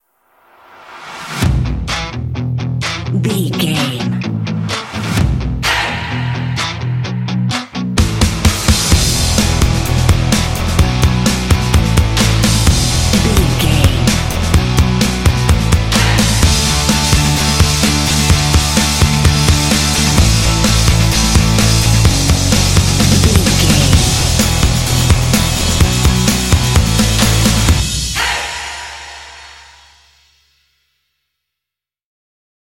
This indie track contains vocal “hey” shots.
Uplifting
Ionian/Major
lively
cheerful
drums
bass guitar
electric guitar
percussion
synth-pop
alternative rock